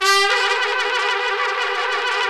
Index of /90_sSampleCDs/Roland LCDP06 Brass Sections/BRS_Tpts FX menu/BRS_Tps FX menu